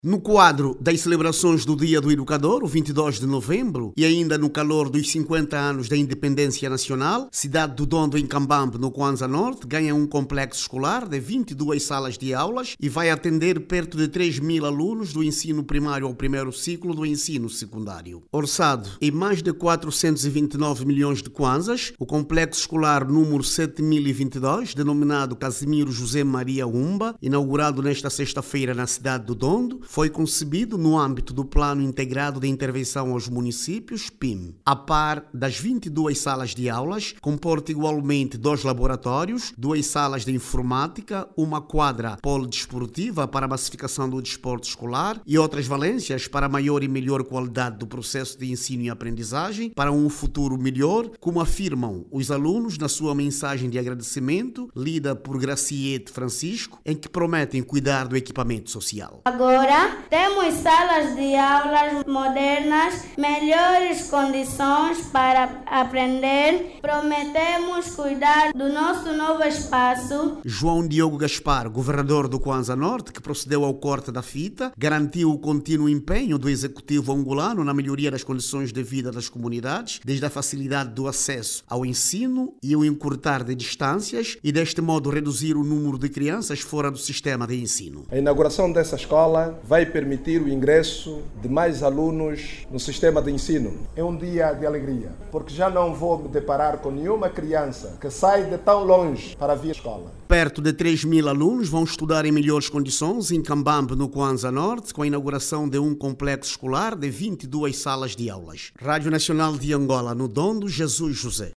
Por ocasião da data, a cidade Dondo, no Cuanza-Norte, está a beneficiar de um novo complexo escolar de 22 salas construído no âmbito do PIIM. Ouça no áudio abaixo toda informação com a reportagem